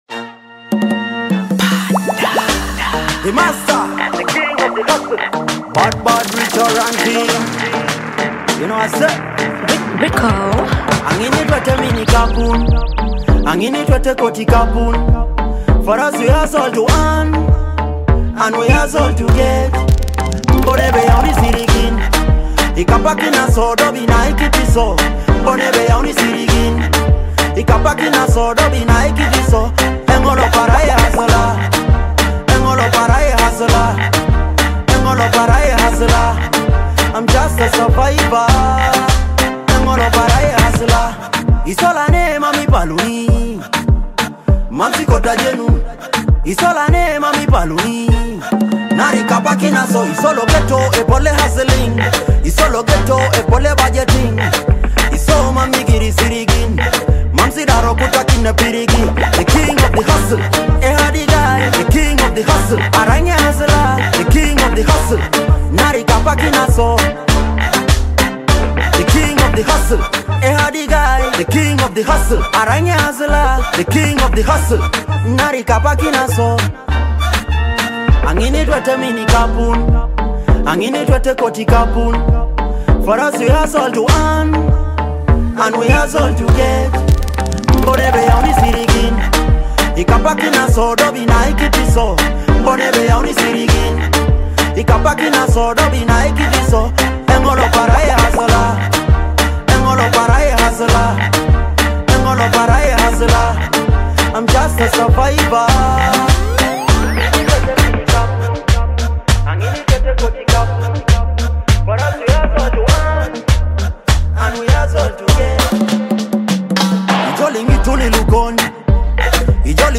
a high-energy Teso dancehall hit about money.